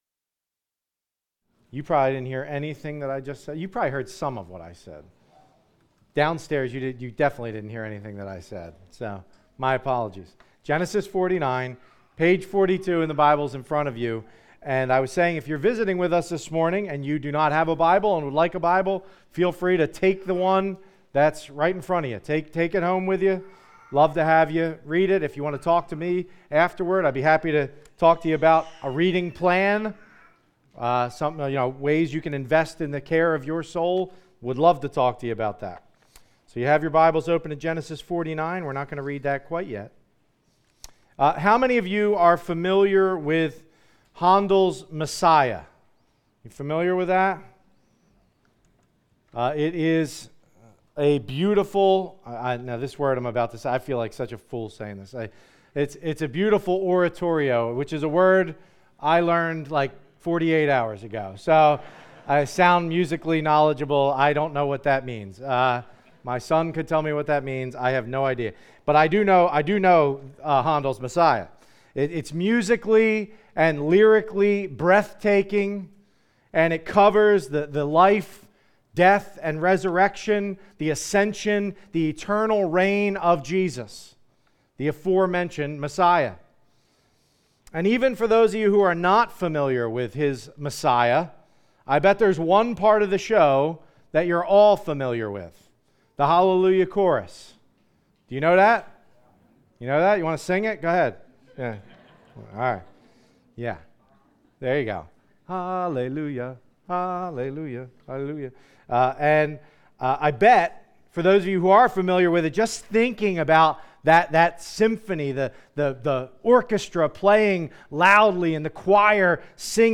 is preaching from the Book of Ruth